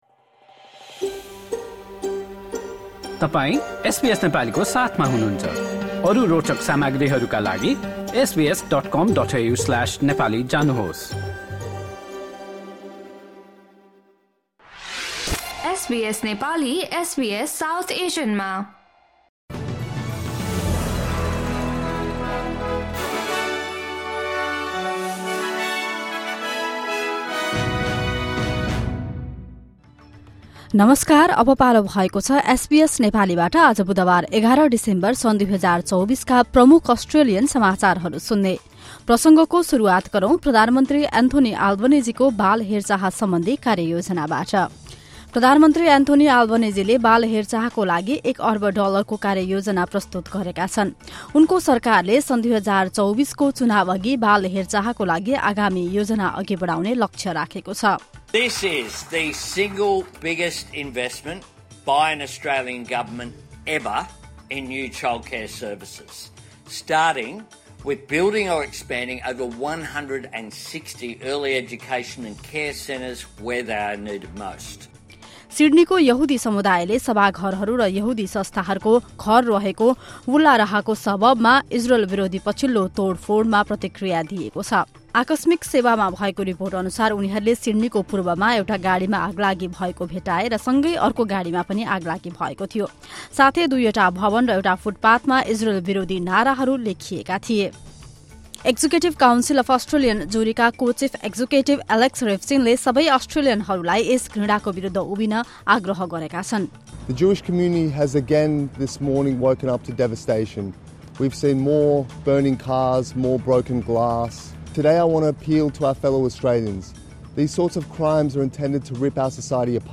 SBS Nepali Australian News Headlines: Wednesday, 11 December 2024